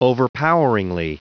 Prononciation du mot overpoweringly en anglais (fichier audio)
Prononciation du mot : overpoweringly